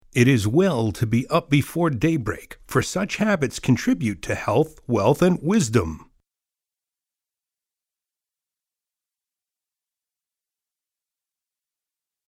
So, I created some more and had them recorded by two talented voice-over actors and offer them here free to share. Each one includes a bit of silence at the end so that it plays once, pauses for a few moments, and then plays again.